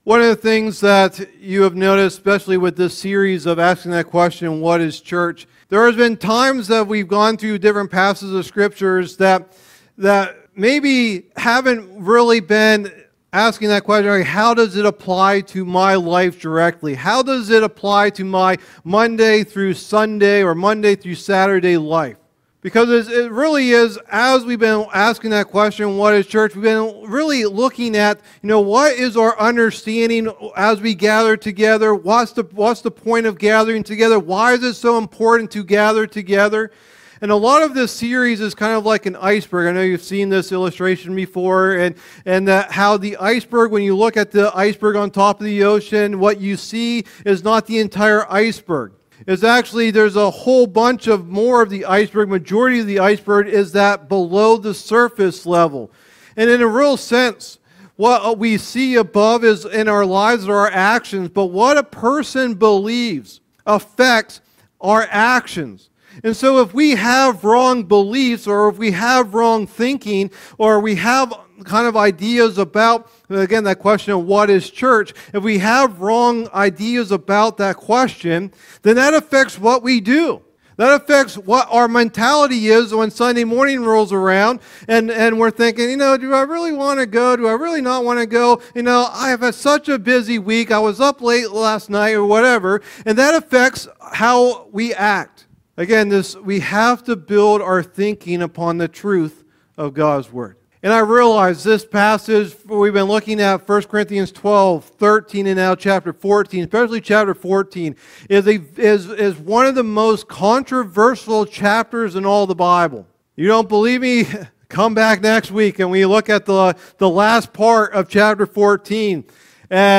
Download Download Reference 1 Corinthians 14:26-33 Sermon Notes 1 Corinthians 14.26-33.pdf Message #13 of the "What is Church?" teaching series What is "Church"?